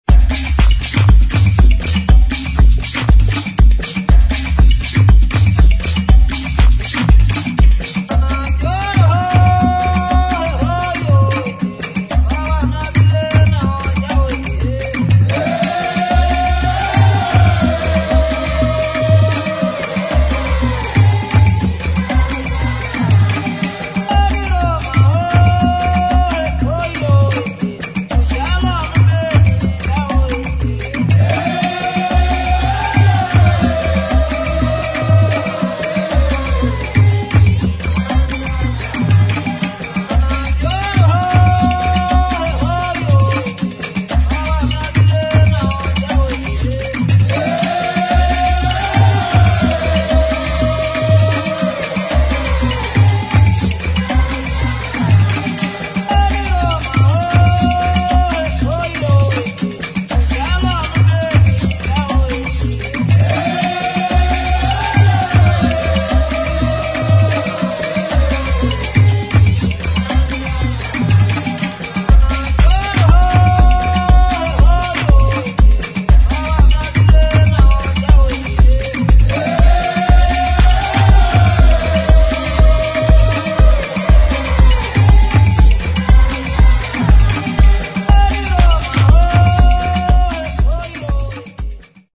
[ HOUSE / AFRO / SOUL ]